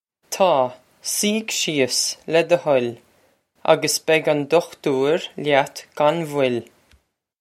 Pronunciation for how to say
Taw. See-ig she-uhs, leh duh huh-ill, a-gus beg on dukh-toor lyat gon vwill.
This comes straight from our Bitesize Irish online course of Bitesize lessons.